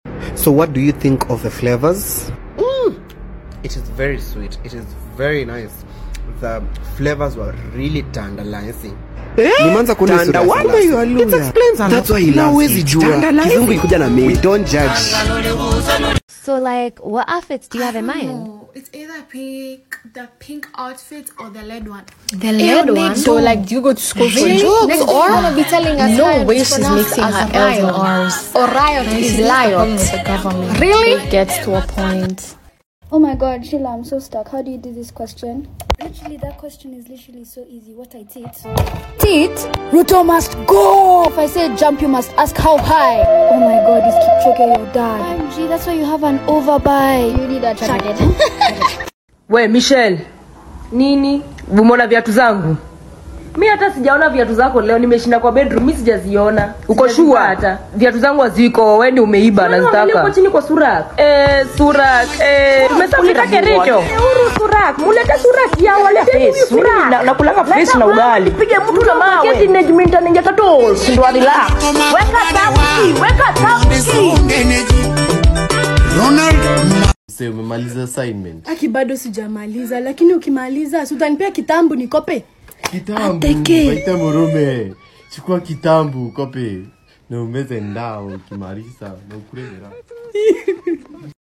ranking kenyan accents slip(my opinion)